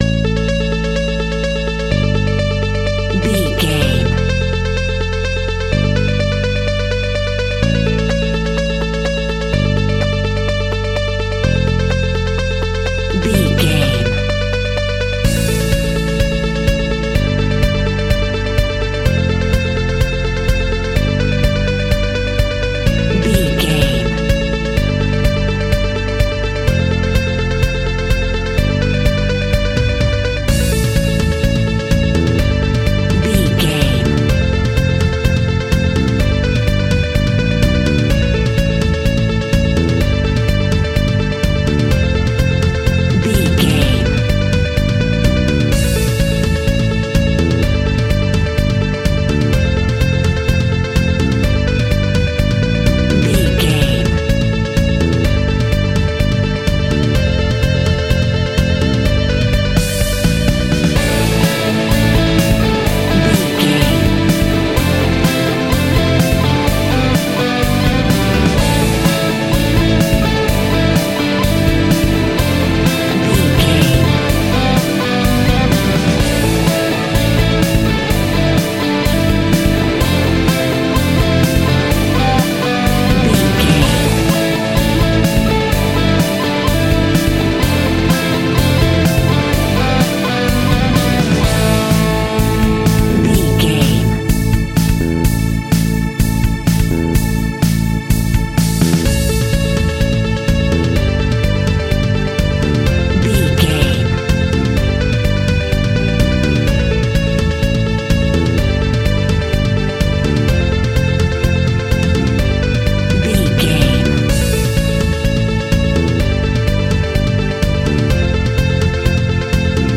Ionian/Major
pop rock
indie pop
energetic
uplifting
upbeat
groovy
guitars
bass
drums
piano
organ